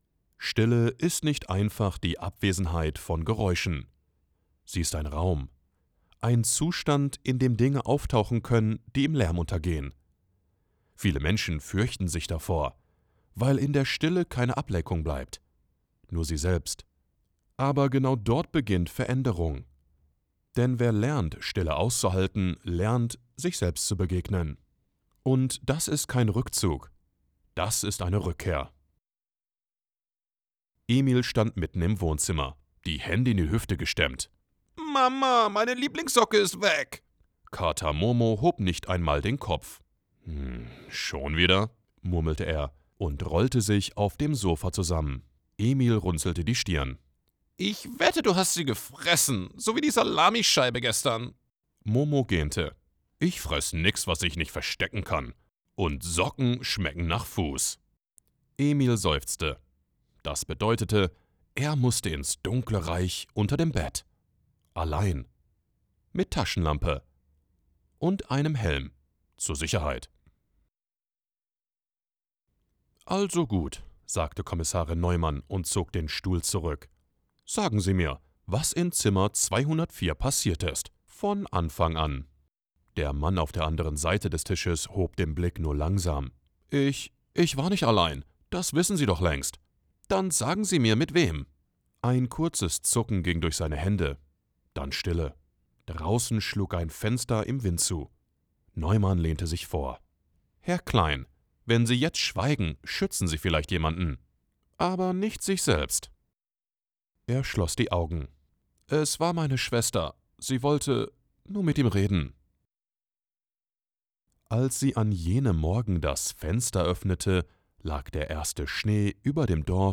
Voice Over Demos
Audiobook
Authentic tone, rich delivery, unforgettable stories